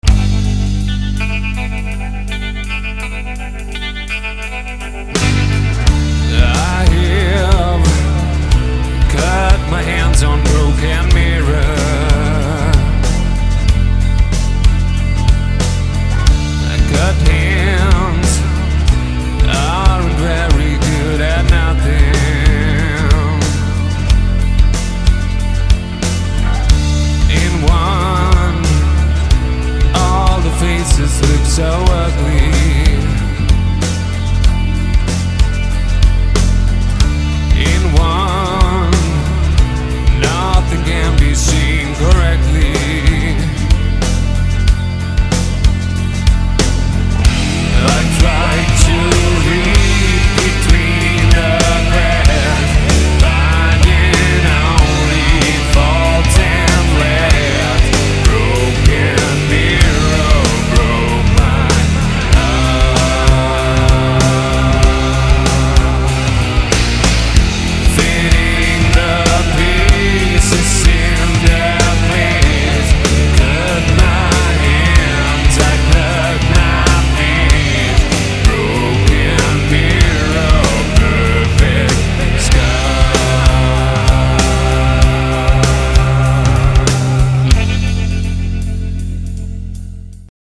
modern, meaningful rock with a darkish twist